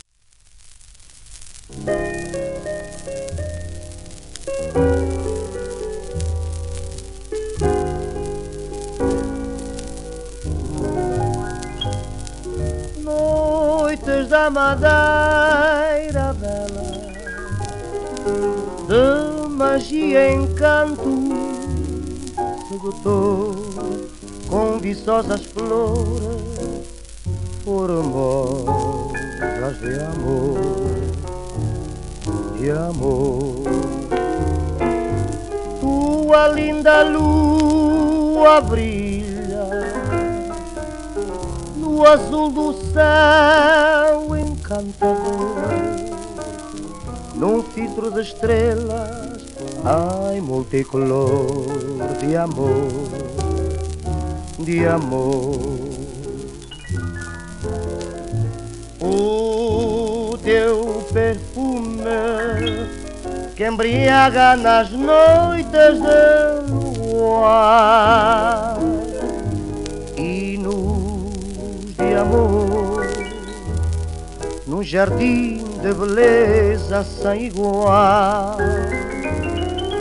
w/オーケストラ
1938年録音